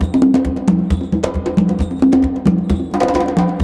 CONGABEAT6-L.wav